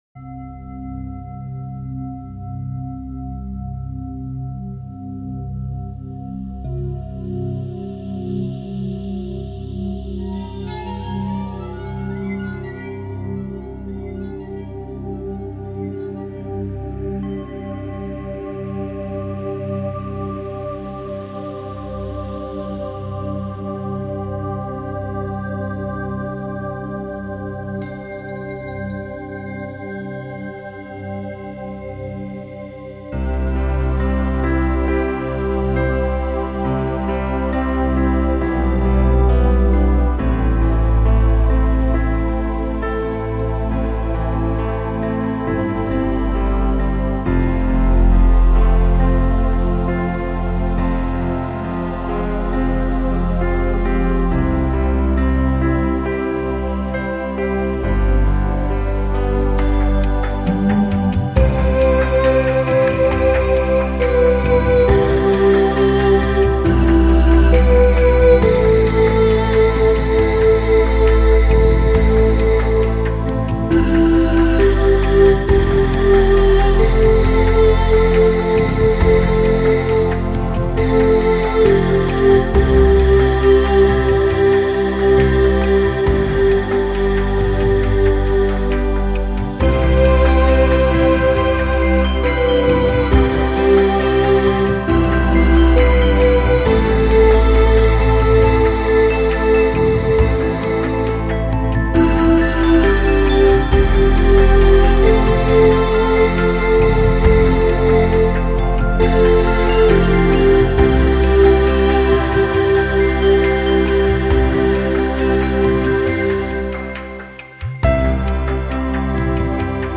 那种恬静纯然的新世纪纯音乐，
很喜欢这张精选，喜欢编者挑选曲目的精心安排，全然的舒服和放松，